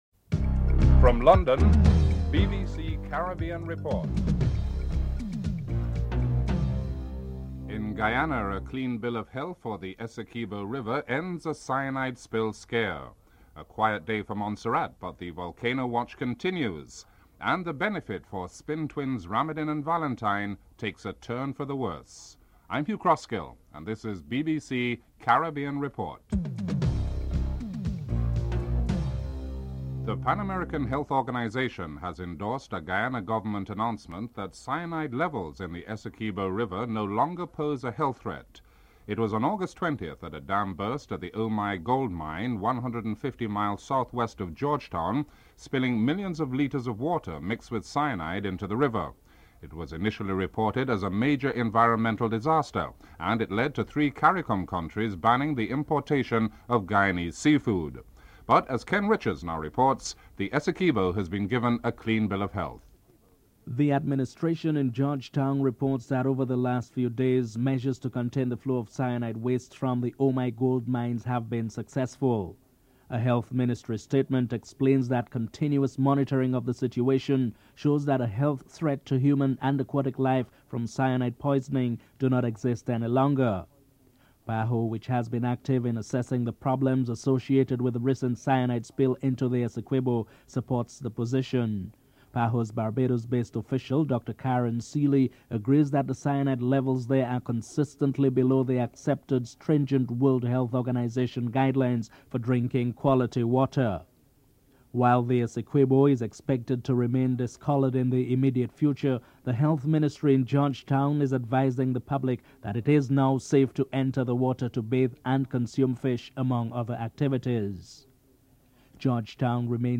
In this report, PAHO has endorsed the Guyanese government's announcement that cyanide levels in the Essequibo River no longer poses a health threat. Guyana's Foreign Minister Clement Rohee comments on the issue that at least three CARICOM states banned Guyanese seafood exports because of the spill. Montserrat's Chief Minister Reuben Meade states that while monitoring of the volcano continues the situation appears to have stabilized.